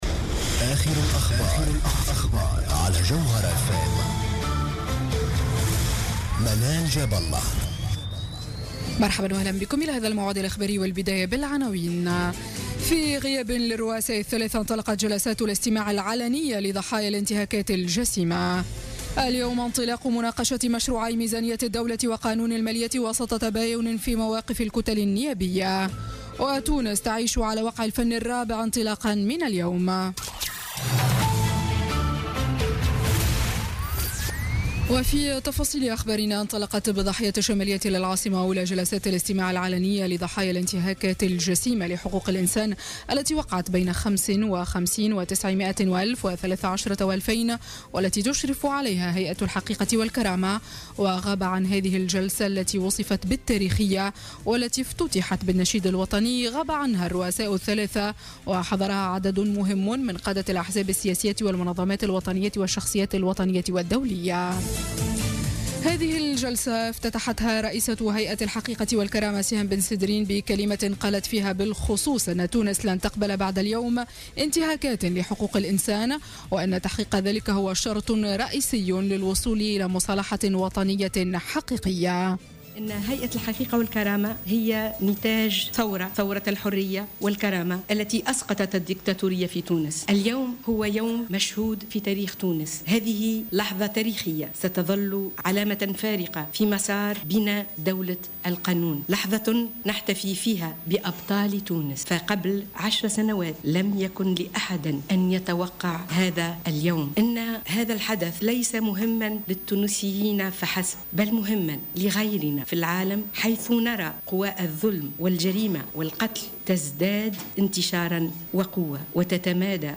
نشرة أخبار منتصف الليل ليوم الجمعة 18 نوفمبر 2016